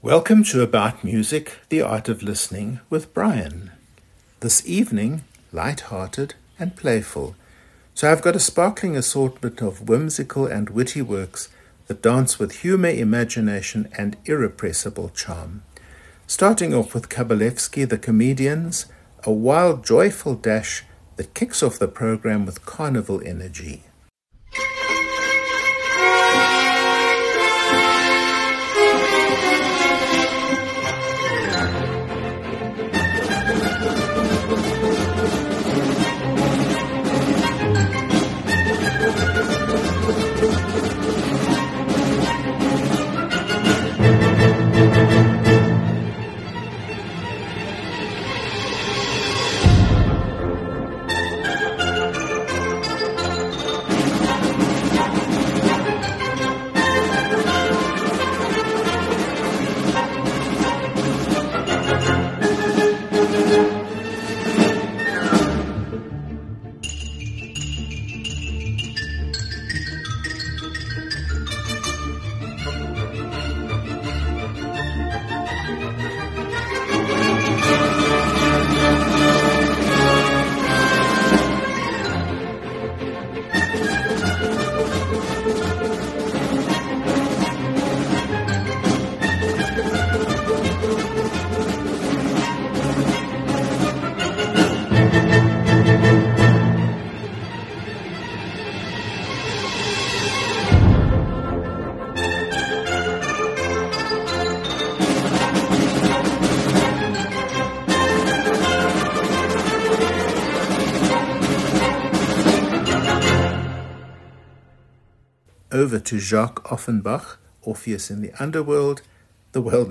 Programme 25: Lighthearted and Playful programme description – a sparkling assortment of whimsical and witty works that dance with humour, imagination, and irrepressible charm.
Kabalevsky, Dmitry The Comedians – Gallop 1:45 A wild, joyful dash that kicks off the programme with carnival energy.
Offenbach, Jacques Orpheus in the Underworld – Can-Can 2:15 This high-kicking number remains the epitome of operetta mischief and sparkle.